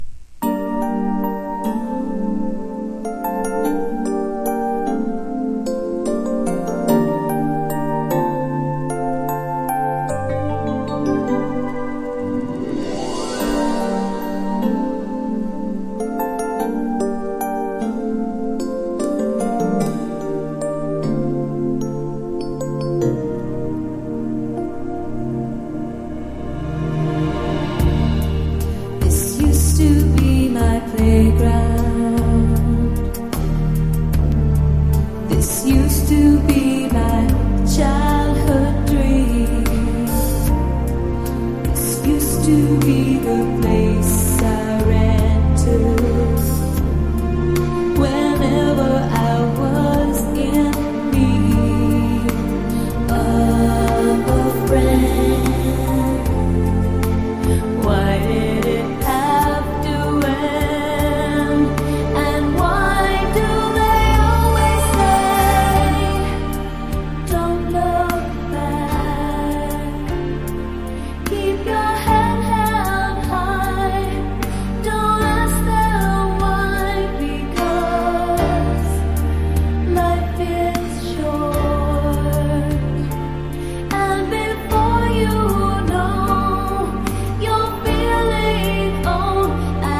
VOCAL & POPS